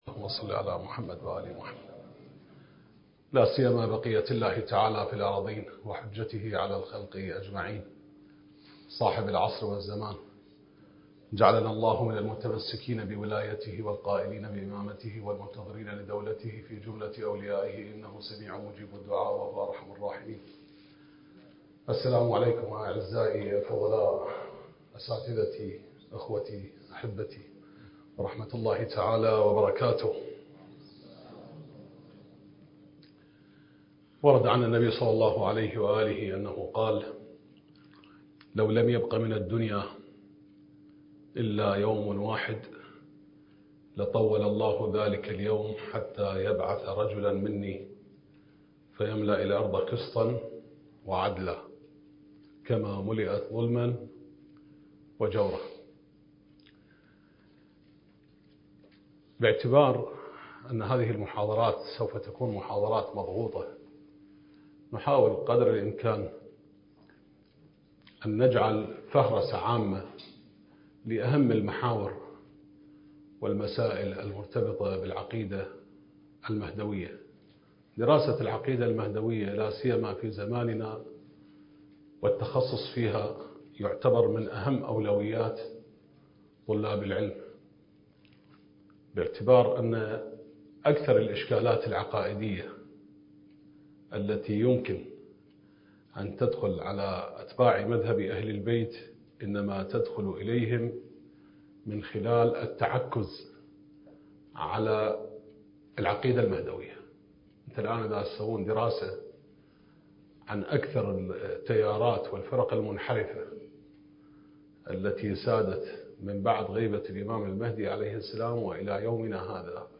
الدورة المهدوية الأولى المكثفة (المحاضرة الرابعة)